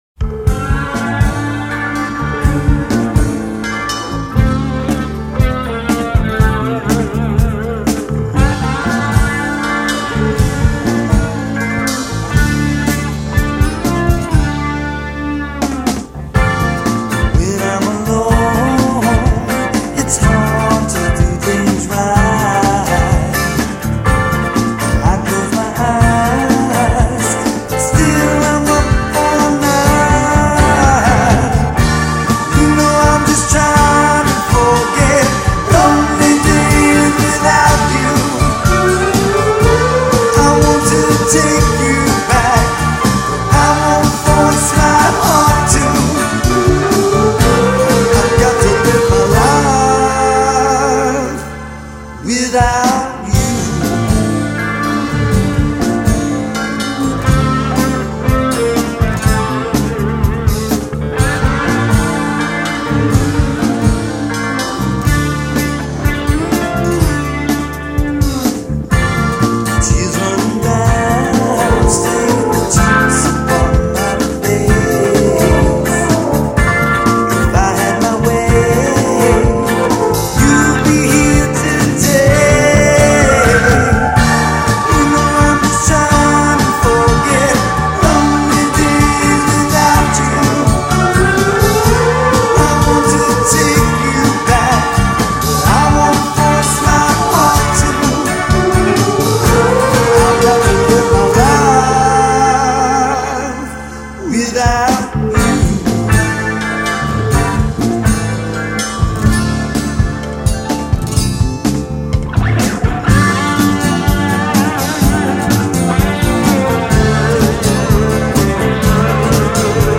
2010 Guitar
lead vocals
bass guitar
drums
Piano